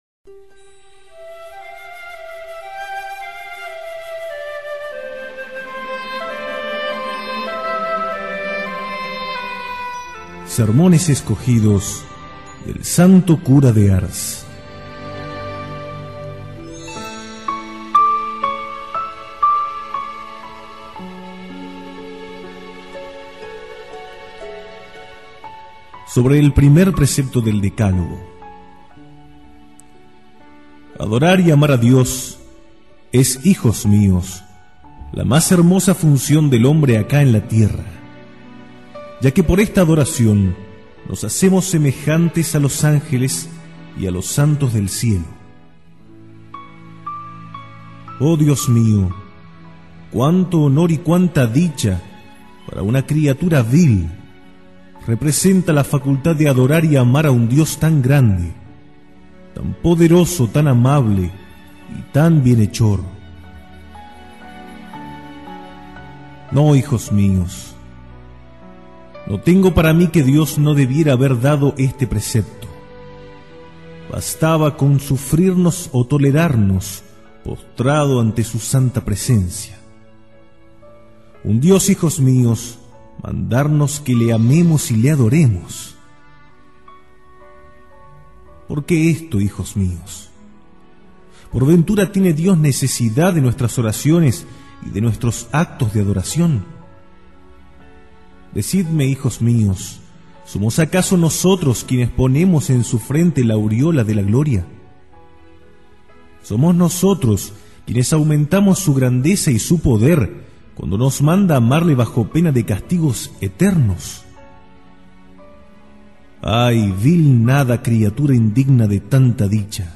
Audio–libros